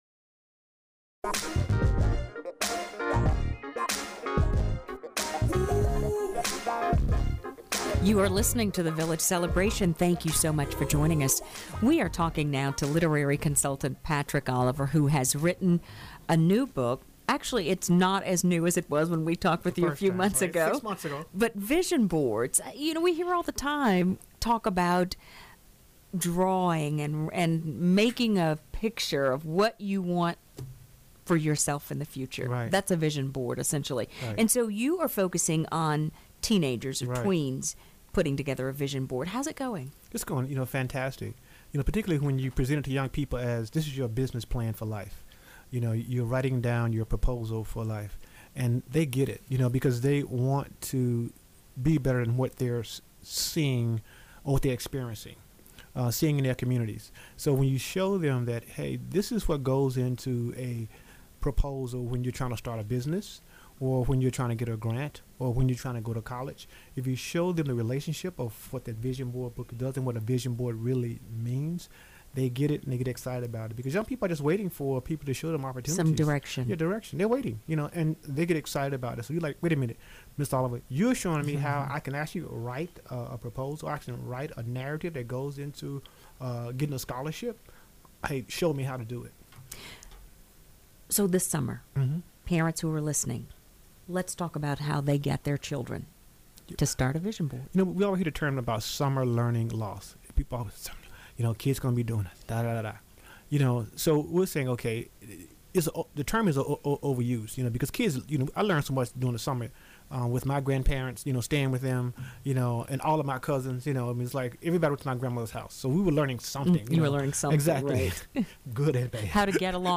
Here is his interview on TheVillageCelebration.